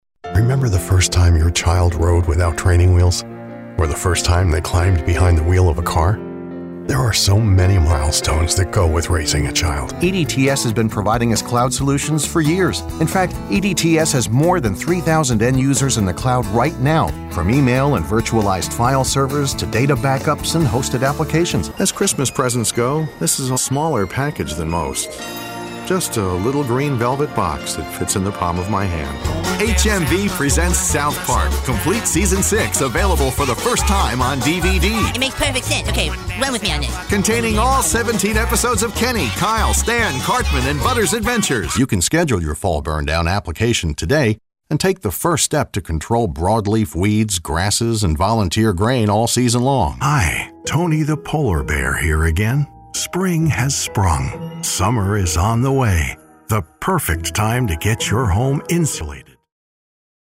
Highly experienced, credible and versatile Voice Actor/Narrator.
Sprechprobe: Werbung (Muttersprache):